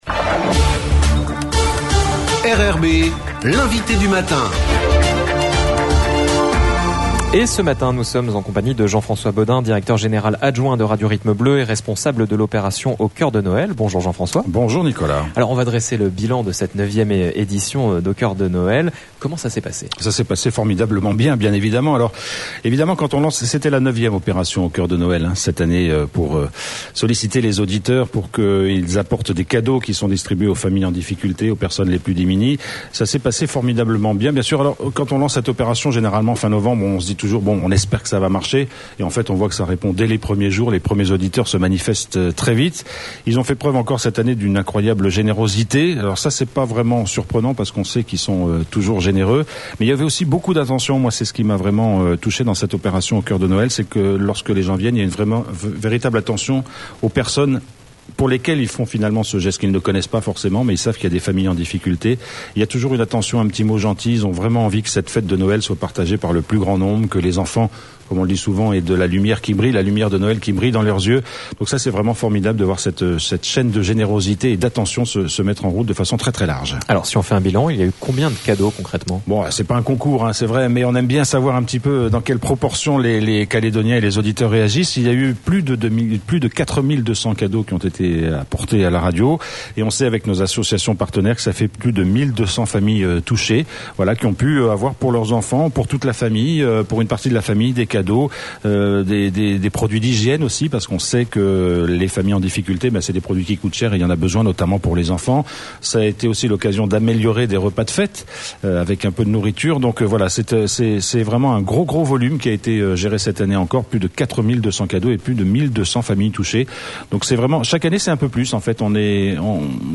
L'INVITE DU MATIN : LUNDI 26 12 22